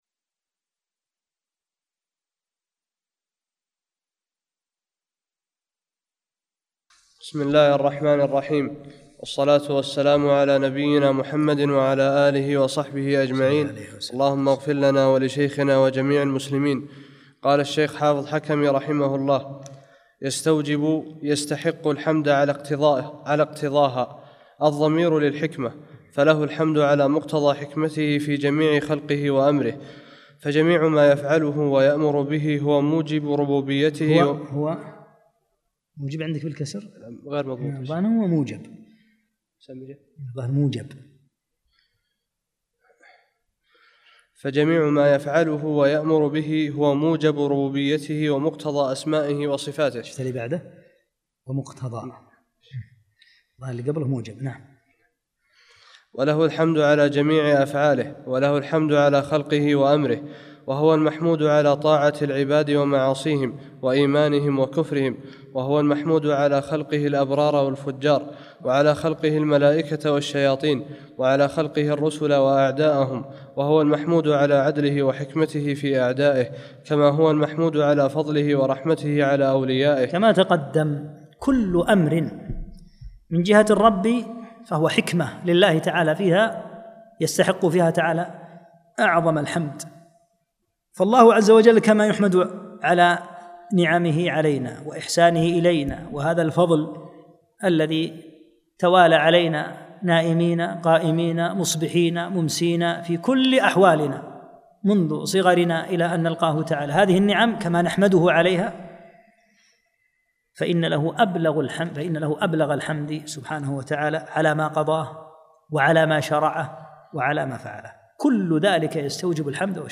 30- الدرس الثلاثون